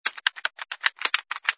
sfx_keyboard_flurry03.wav